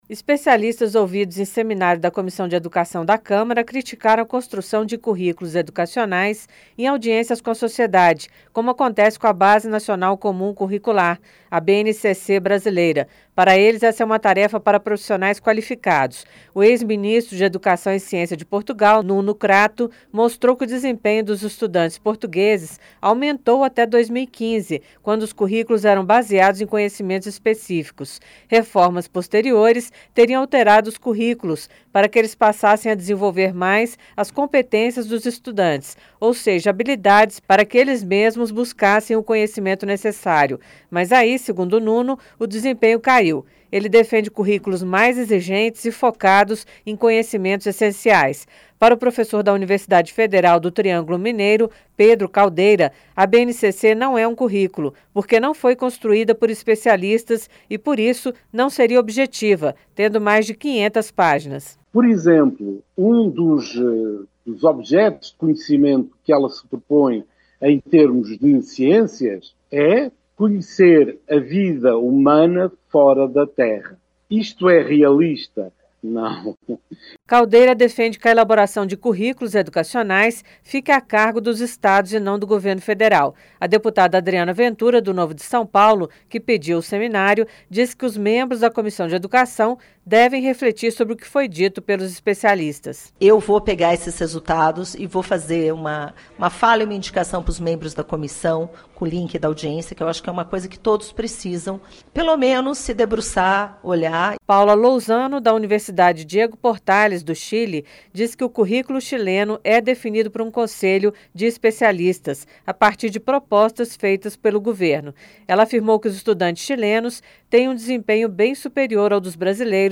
ESPECIALISTAS DEFENDEM CURRÍCULOS EDUCACIONAIS FEITOS POR TÉCNICOS E FOCADOS EM CONHECIMENTOS ESPECÍFICOS. A REPORTAGEM